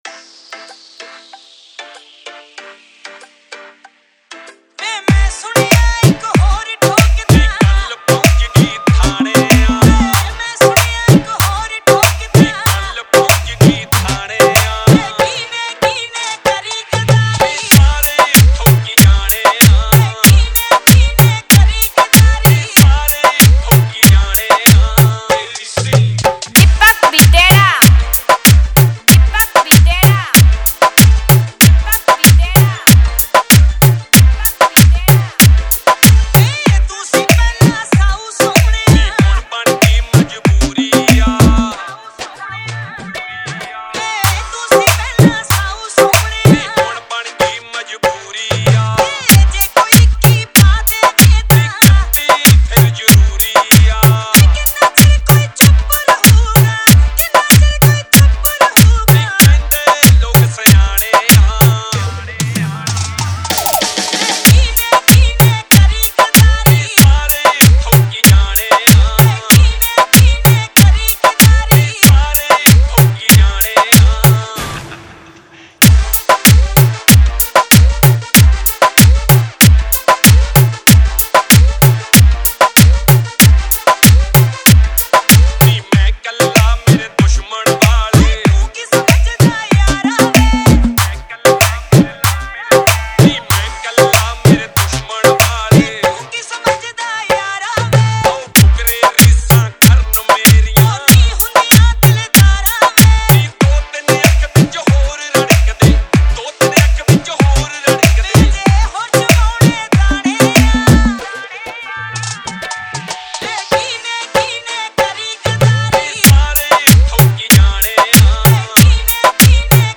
Punjabi Remix